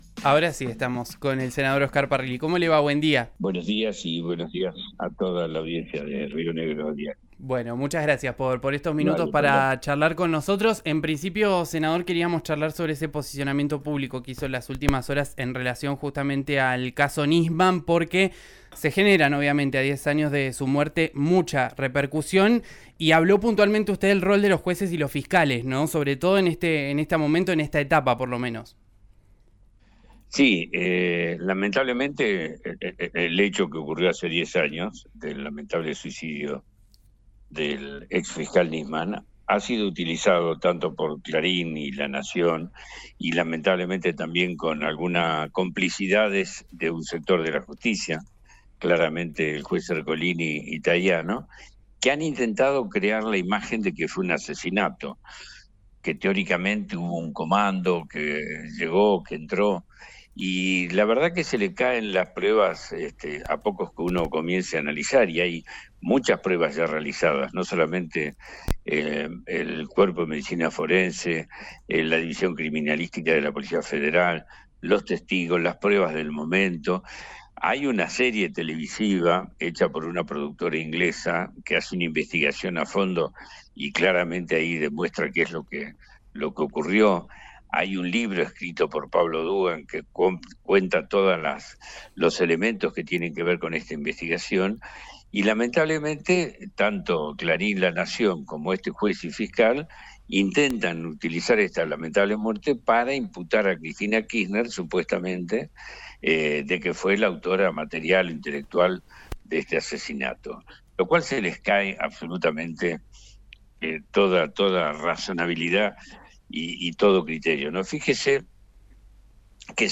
Escuchá a Oscar Parrilli, senador de Unión por la Patria, en RÍO NEGRO RADIO:
En una entrevista exclusiva con RÍO NEGRO RADIO, el histórico dirigente del PJ habló también del gobernador de Neuquén, Rolando Figueroa y lo acusó de ser otra versión de Milei.